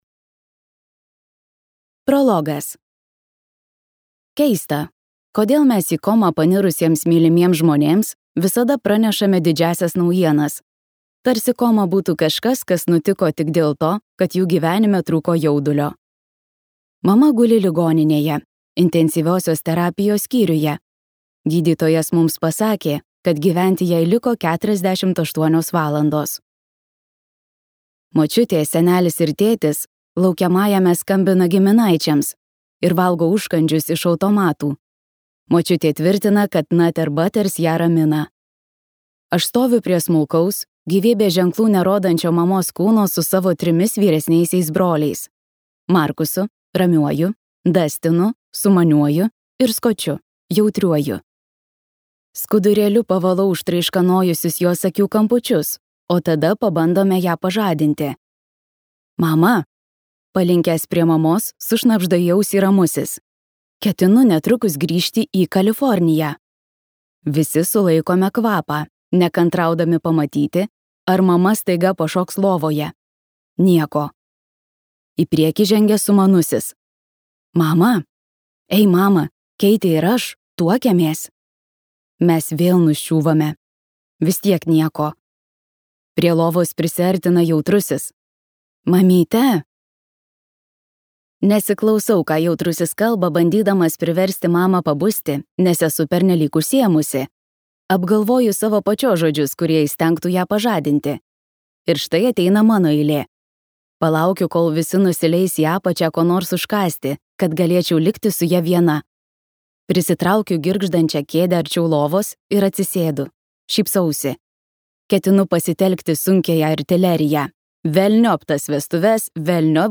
Džiaugiuosi, kad mirė mano mama | Audioknygos | baltos lankos